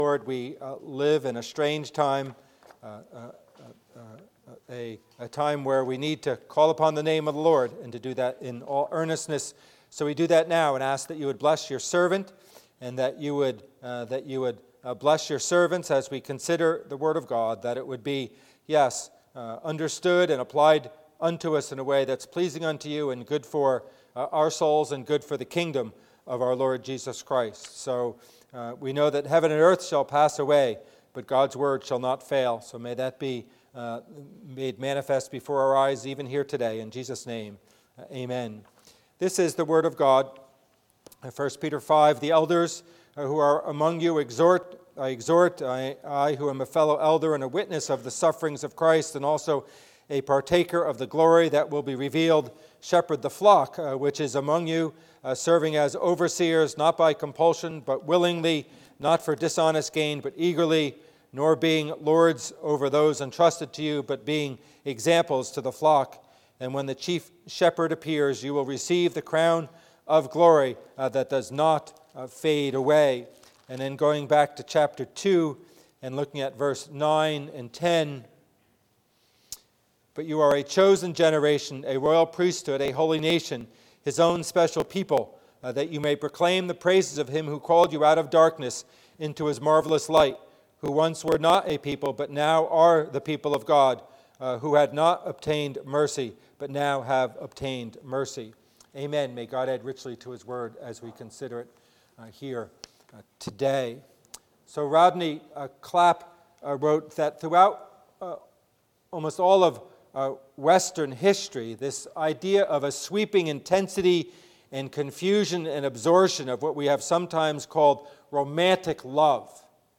Sermon: Kibitzing, Friendship and Community vs. Atomization and Being Alone
Service Type: Worship Service